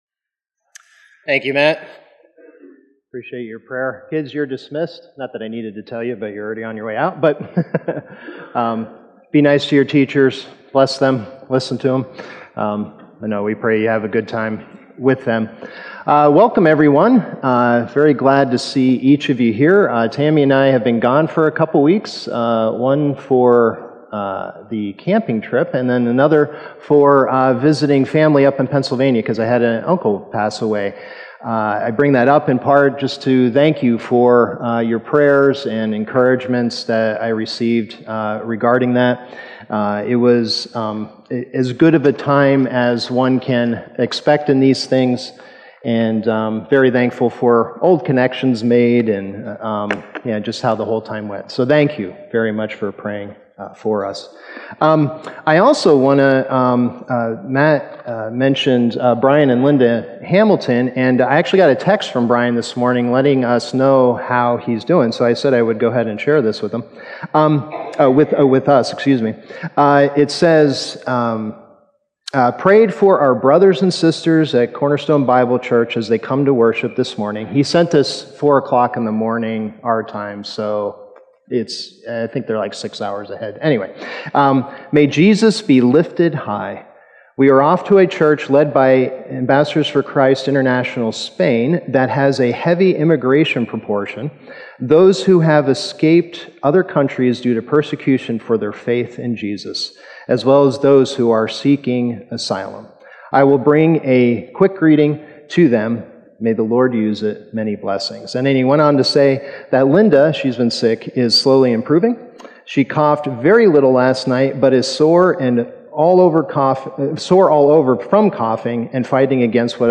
Sermon Detail
October_27th_Sermon_Audio.mp3